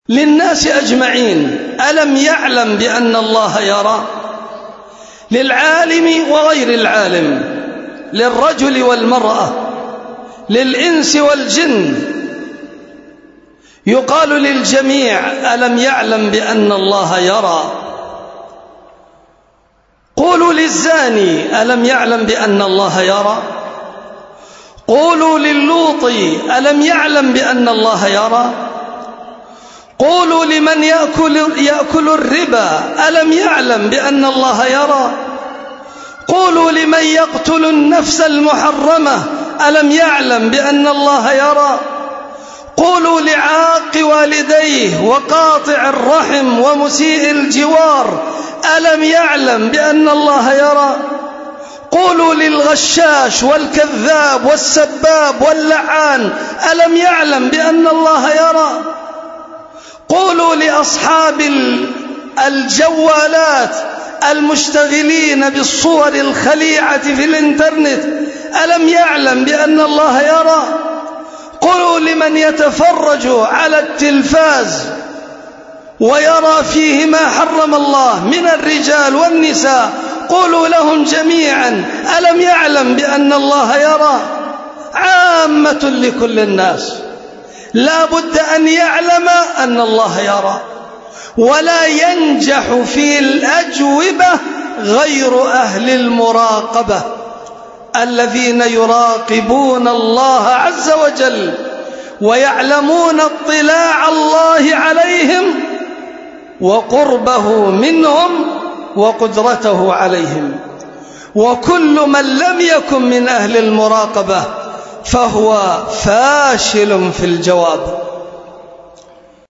خطبة